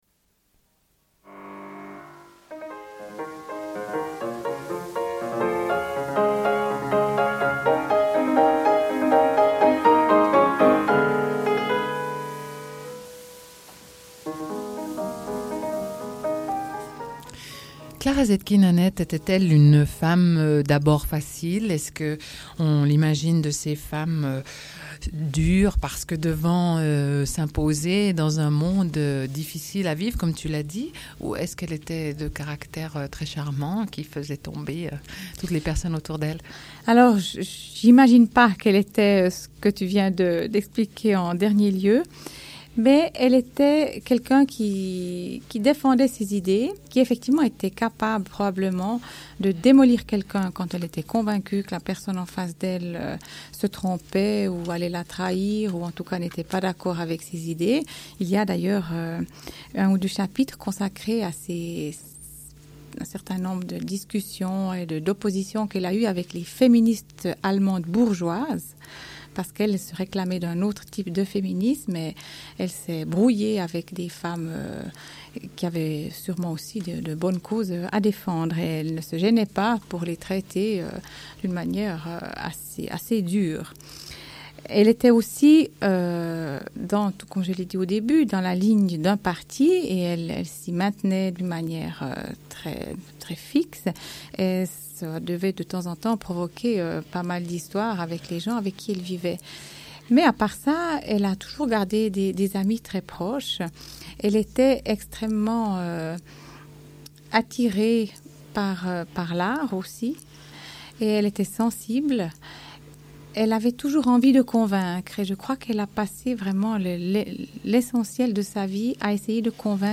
Une cassette audio, face A31:31